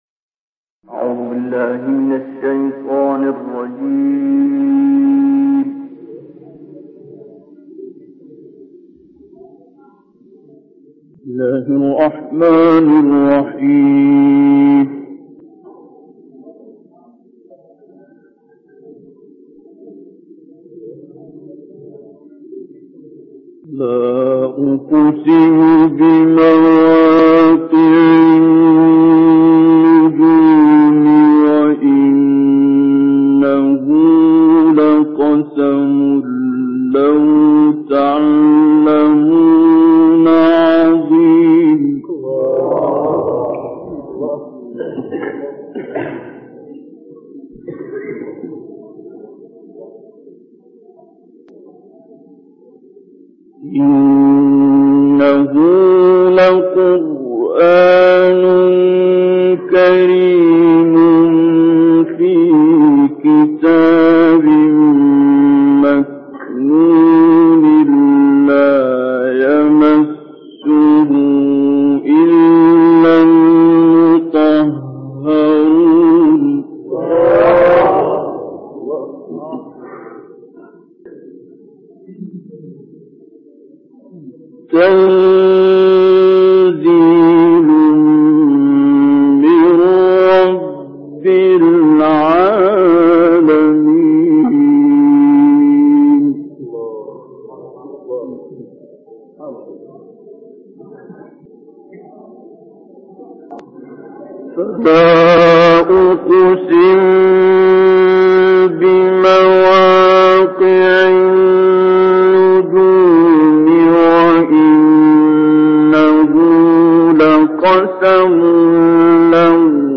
در این بخش از ضیاءالصالحین، تلاوت زیبای سوره واقعه آیات ۷۵ الی آخر سوره طارق را با صدای دلنشین استاد شیخ عبدالباسط عبدالصمد به مدت 16 دقیقه با علاقه مندان به اشتراک می گذاریم.